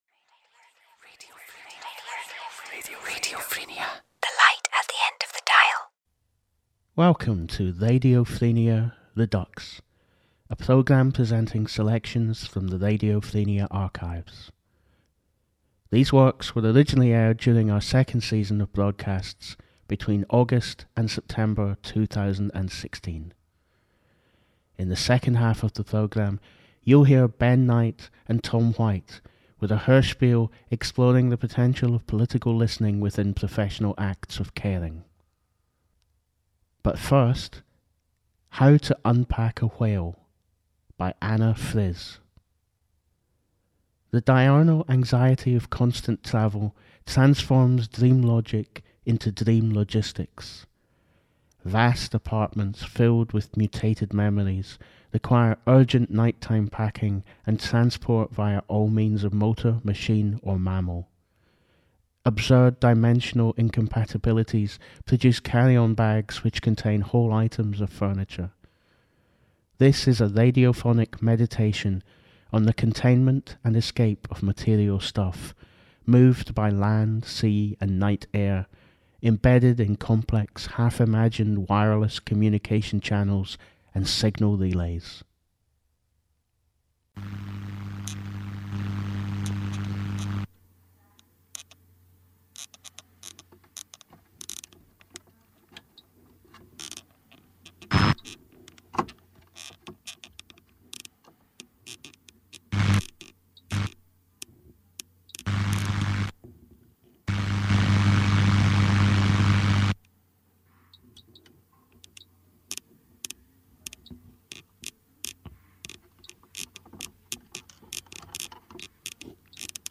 a radiophonic meditation on the containment and escape of material stuff, moved by land, sea and night air, embedded in complex, half-imagined wireless communication channels and signal relays.
and composed of interviews with care workers of all kinds